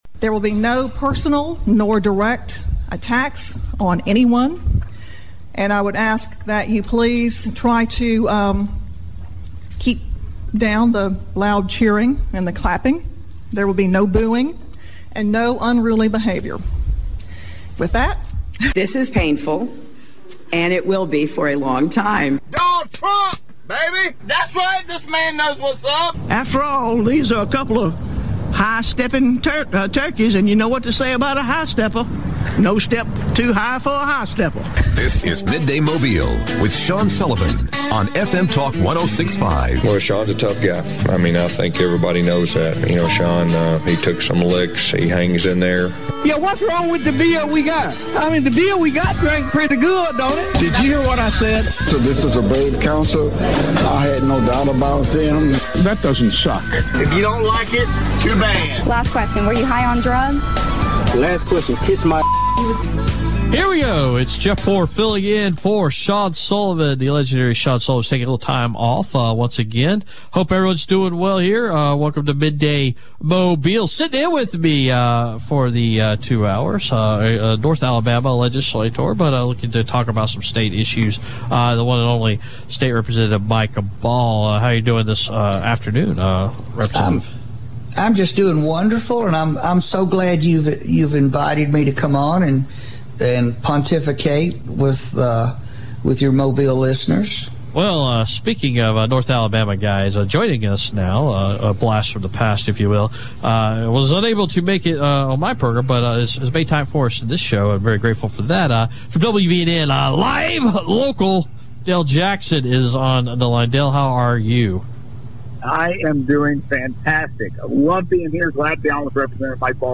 State Representative Mike Ball guest hosts Midday Mobile